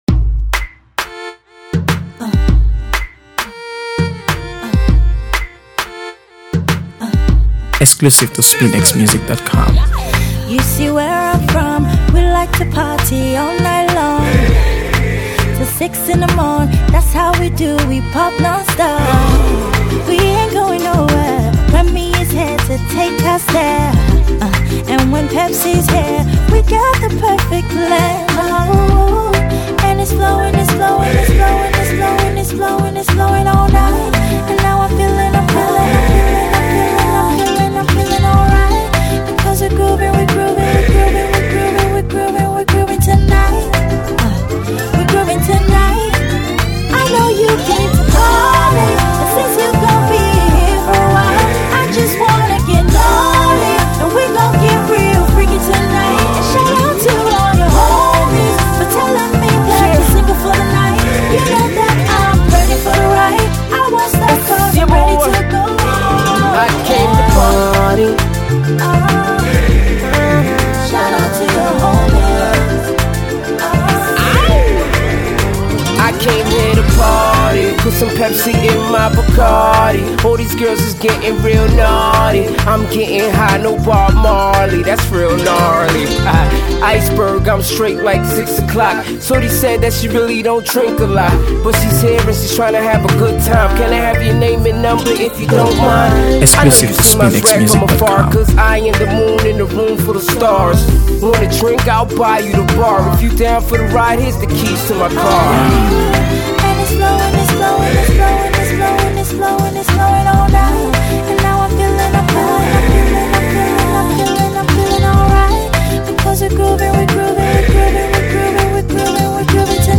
AfroBeats | AfroBeats songs
dynamic and energetic anthem